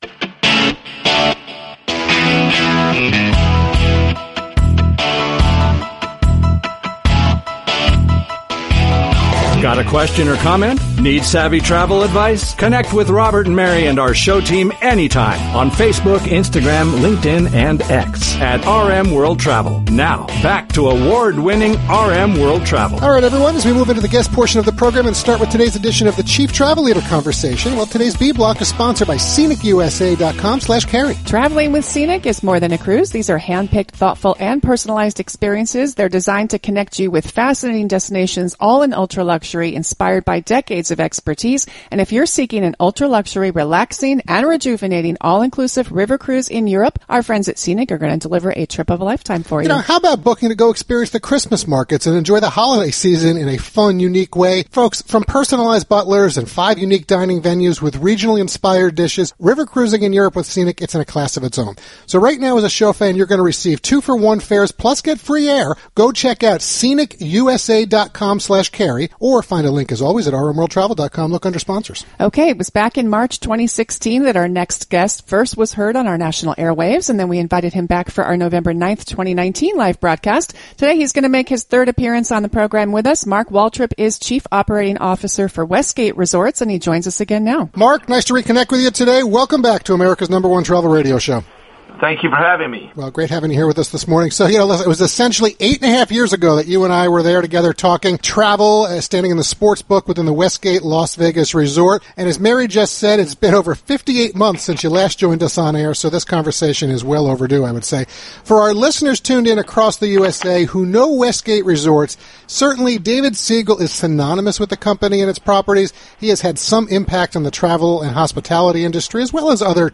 live national broadcast of America’s #1 Travel Radio Show
interview